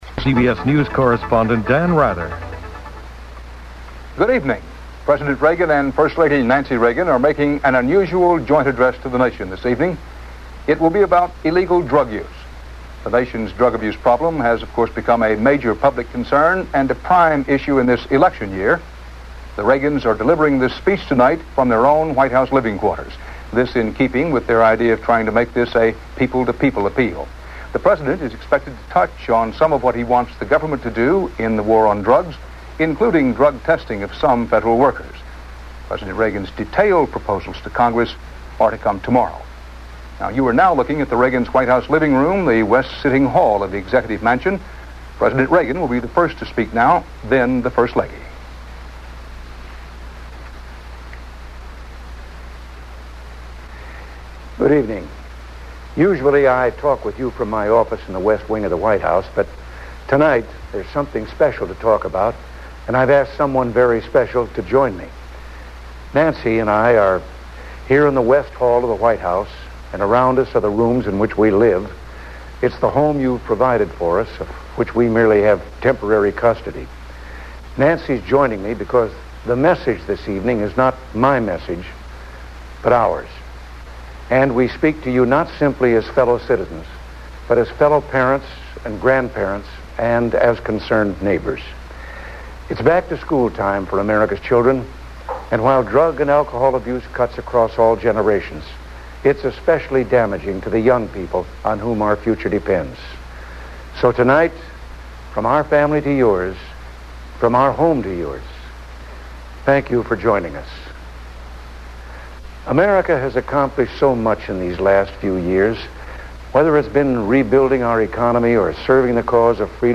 U.S. President Ronald Reagan and First Lady Nancy Reagan address the nation on combatting drug abuse